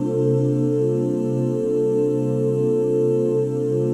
OOH C MIN9.wav